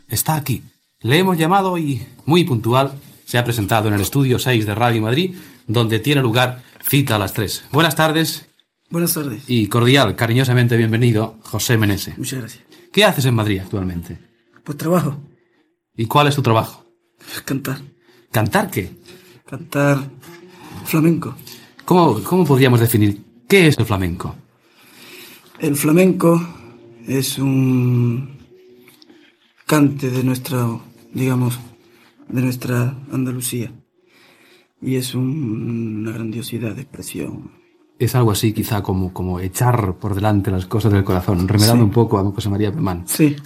Inici de l'entrevista al cantant flamenc José Meneses
Entreteniment